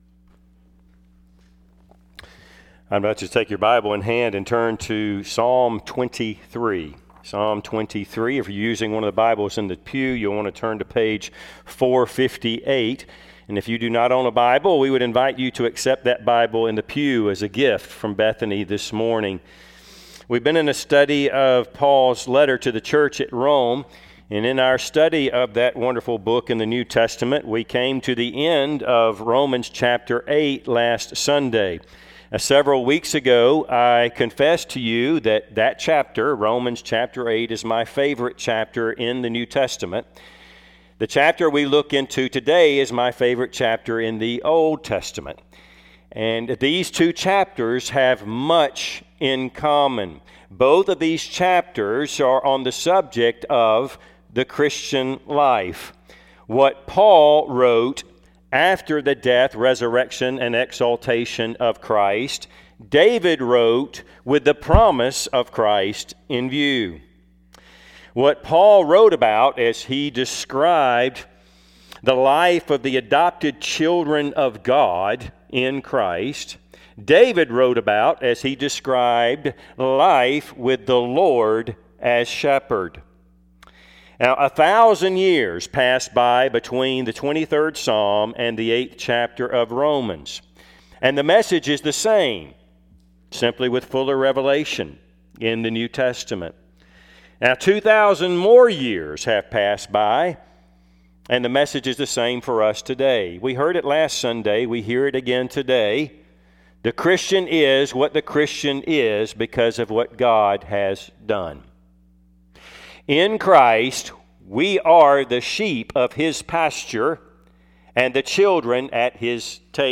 Passage: Psalm 23:1-6 Service Type: Sunday AM